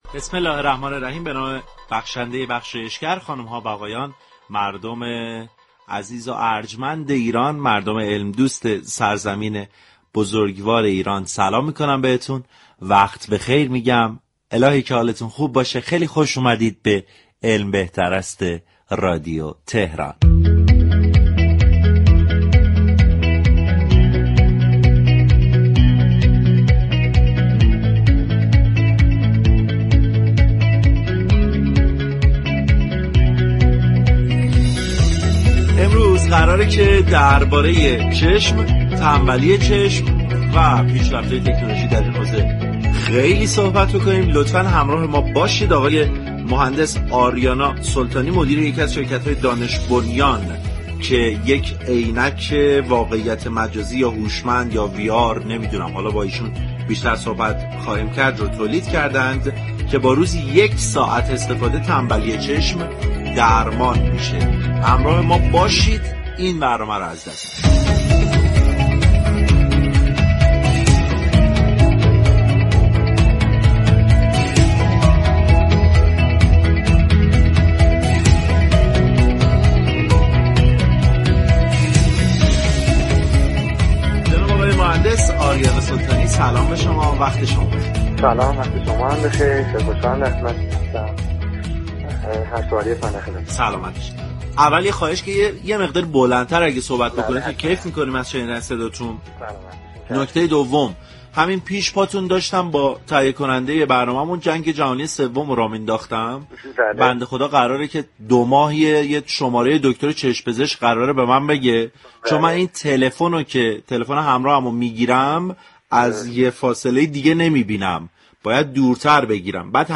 در گفت و گو با «علم بهتر است»